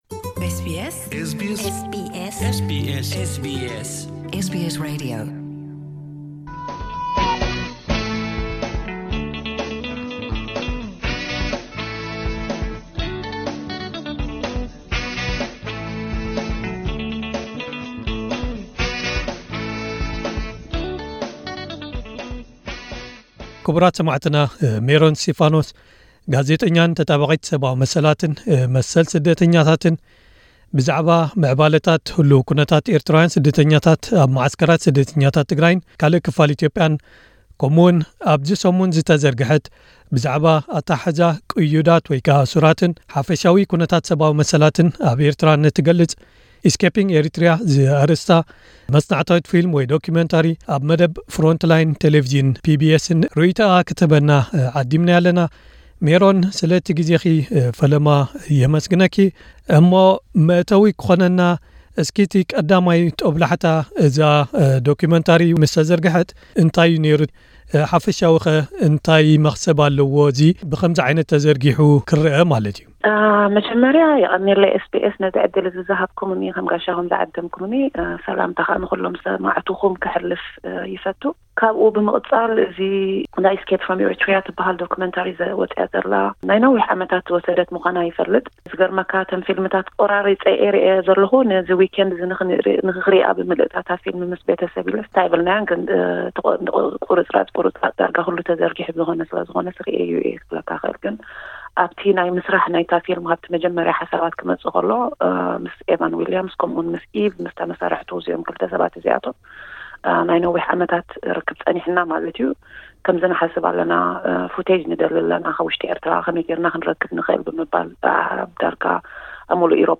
ቃለመሕትት ምስ ጋዜጠኛታት ተጣባቒት ሰብኣዊ መሰላትን ስደተኛታትን